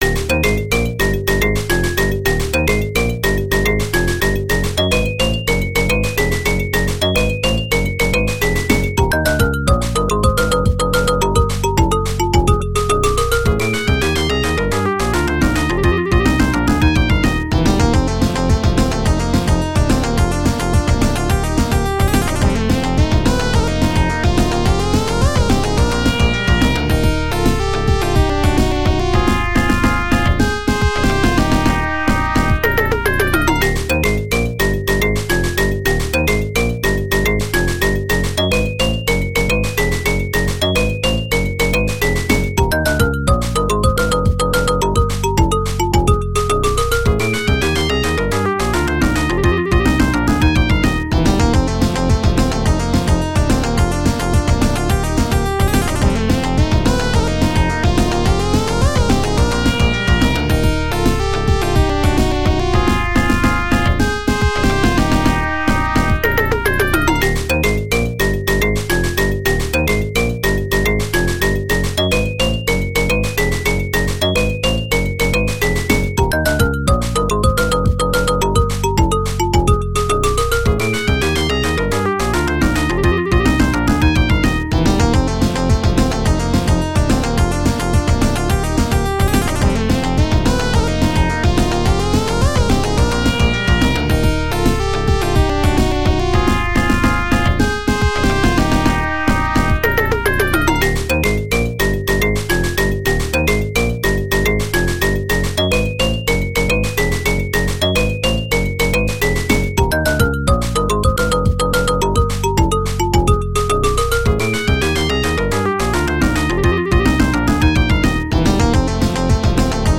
Genesis style